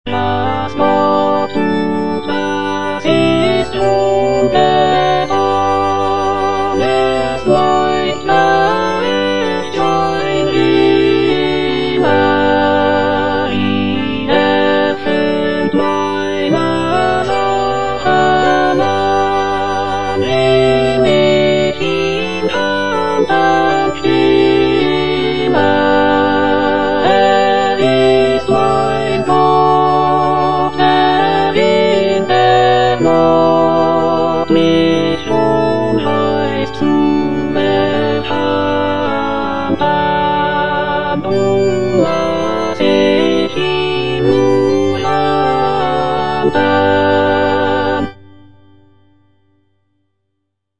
Cantata
Alto (Emphasised voice and other voices) Ads stop